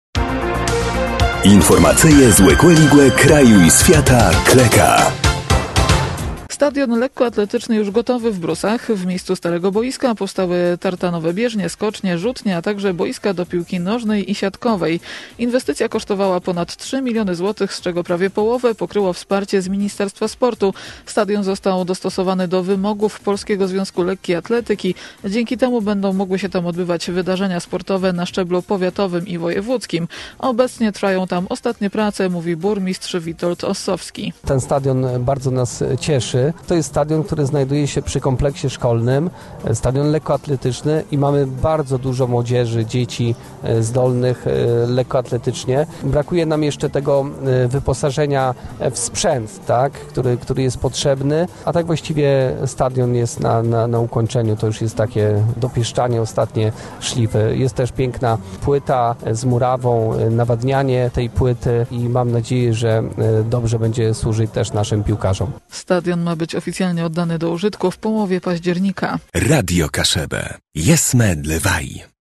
Obecnie trwają tam ostatnie prace – mówi burmistrz Witold Ossowski.